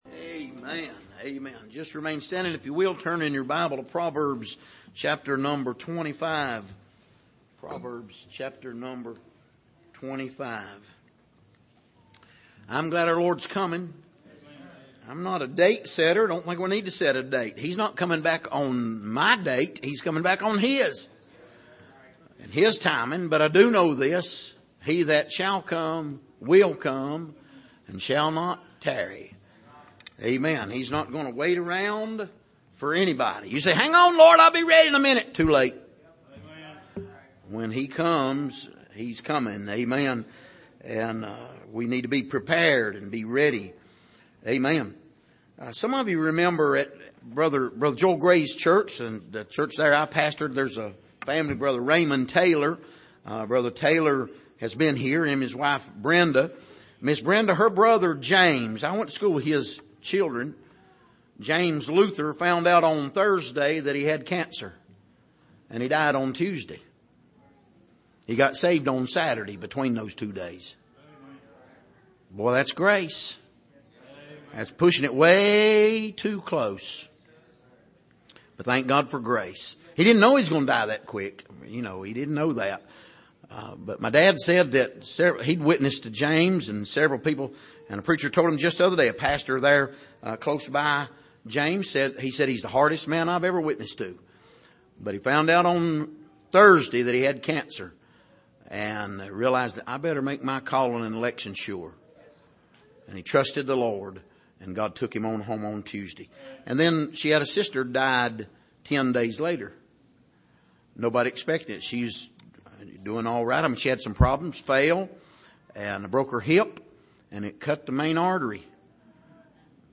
Passage: Proverbs 25:8-14 Service: Sunday Evening